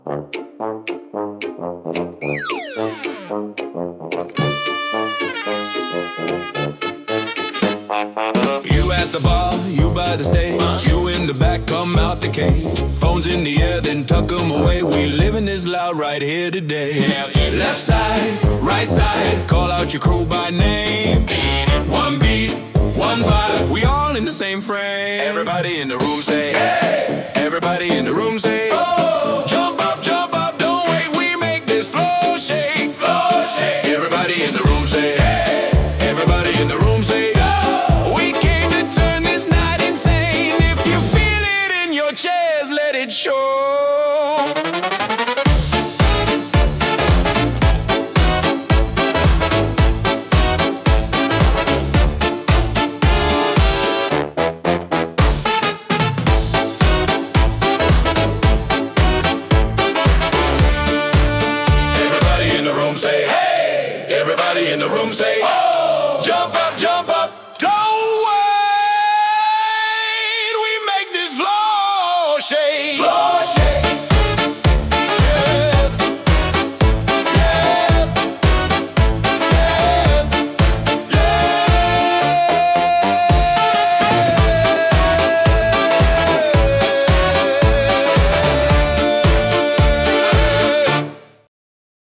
おどけたようなチューバやブラス、そして陽気な男性ボーカルが、いたずら好きな世界観を演出します。